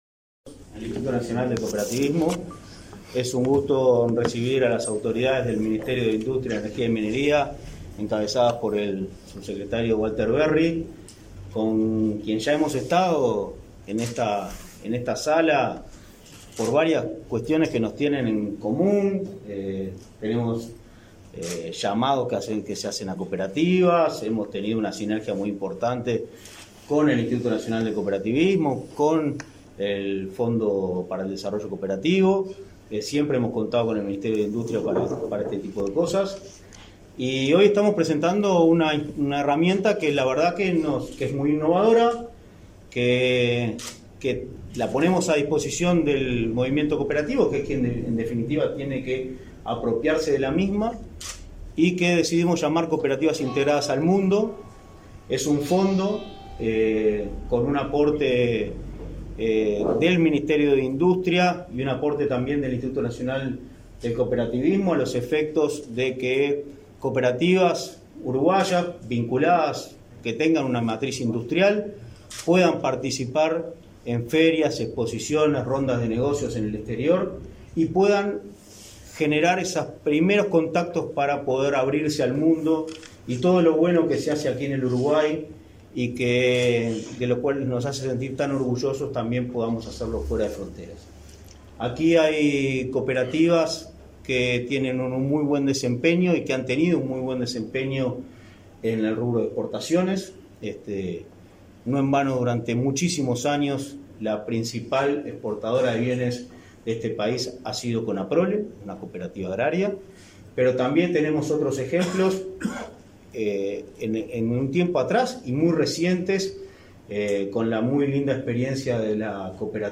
Conferencia de prensa por presentación de herramienta para facilitar inserción internacional de cooperativas
El Instituto Nacional del Cooperativismo (Inacoop) presentó, este 3 de mayo, la herramienta “Cooperativas integradas al mundo”, con la que se busca facilitar la inserción internacional de cooperativas y organizaciones de la economía social y solidaria. Participaron de la actividad el subsecretario de Industria, Energía y Minería, Walter Verri, y el presidente del Inacoop, Martín Fernández.